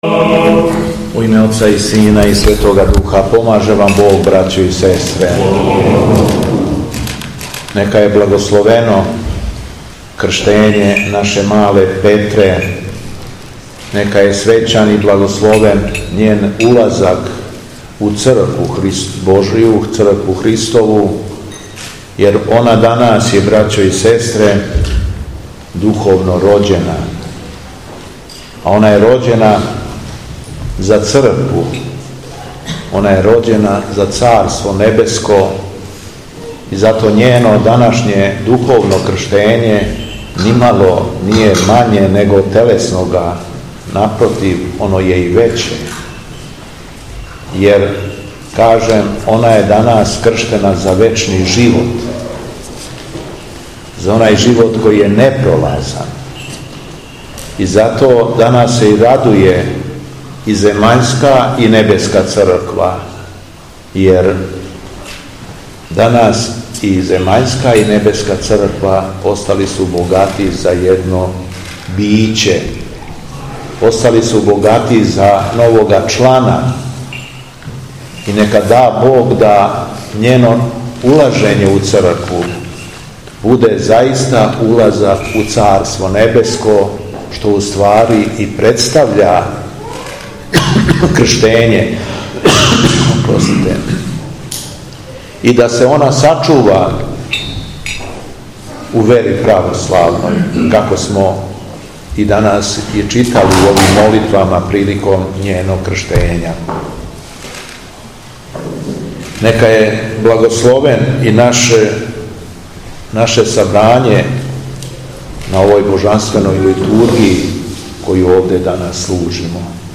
На празник Светих мученица Вере, Наде и Љубави и мајке им Софије, 30. септембра 2023. године, у храму Светог великомученика Димитрија у Великој Иванчи...
Беседа Његовог Преосвештенства Епископа шумадијског г. Јована